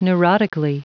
Prononciation du mot neurotically en anglais (fichier audio)